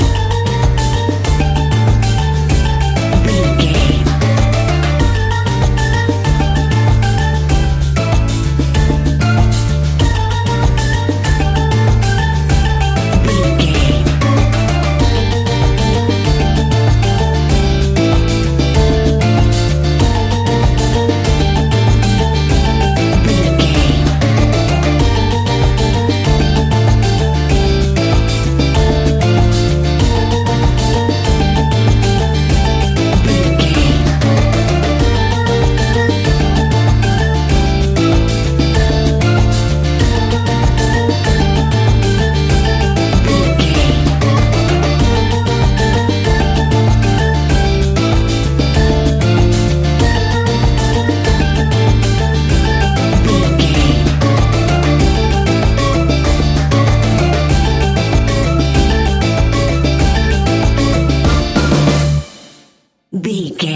Aeolian/Minor
steelpan
calpso groove
drums
percussion
bass
brass
guitar